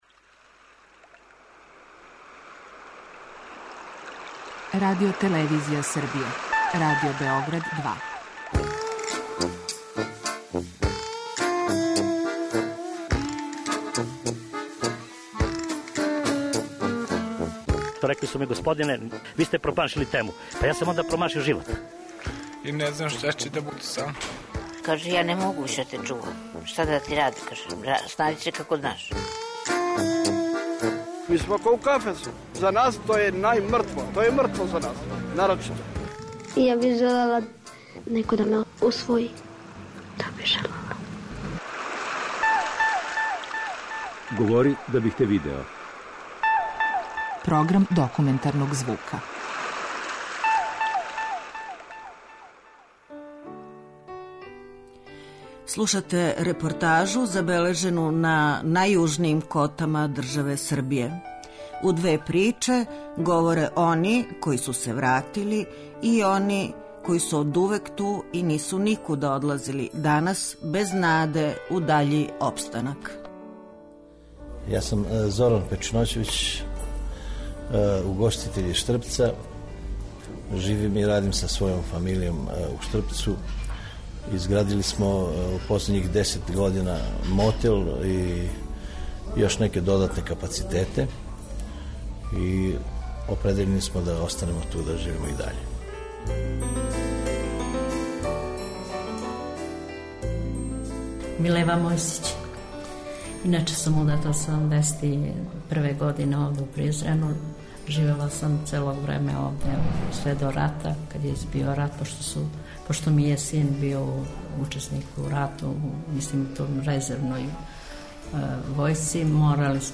Документарни програм
преузми : 10.69 MB Говори да бих те видео Autor: Група аутора Серија полусатних документарних репортажа, за чији је скупни назив узета позната Сократова изрека: "Говори да бих те видео".
Две различите приче забележене су у Штрпцу и Призрену.